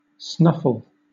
Ääntäminen
Southern England
IPA : /ˈsnʌfəl/